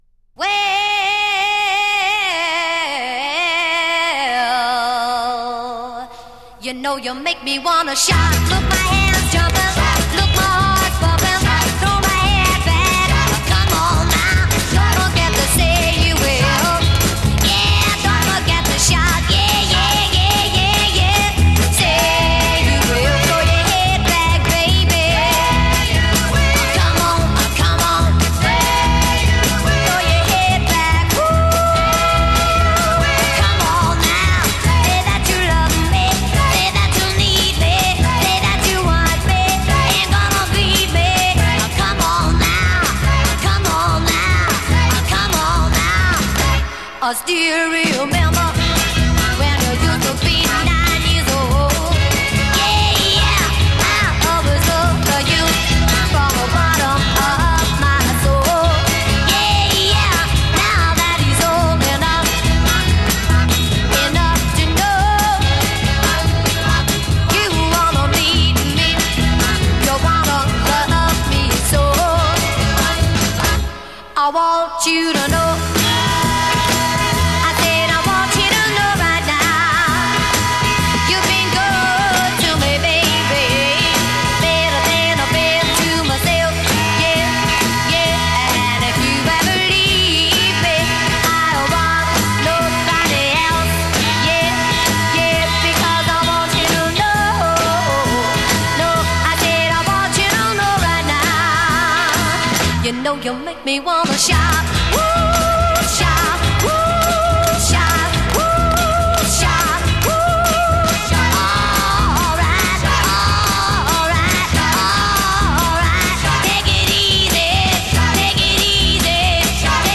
guitar
drums
backup singers
A Verse 1 2:06   Text reduces to responsorial vocables.